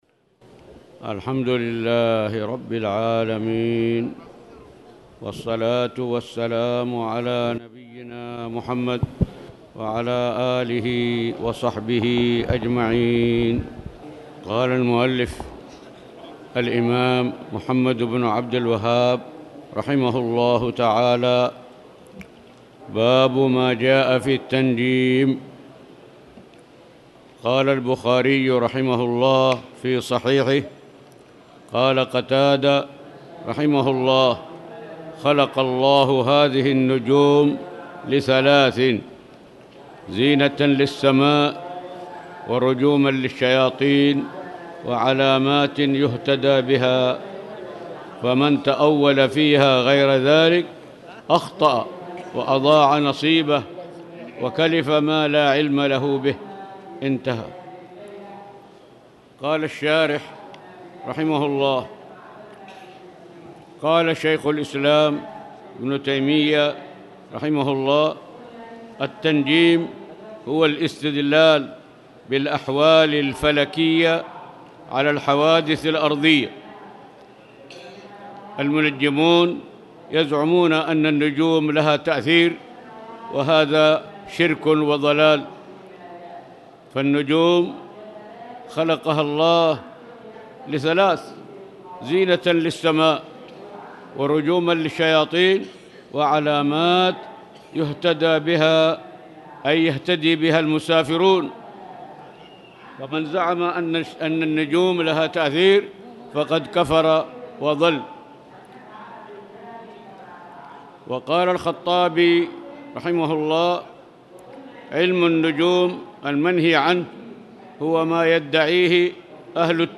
تاريخ النشر ٢٢ محرم ١٤٣٨ هـ المكان: المسجد الحرام الشيخ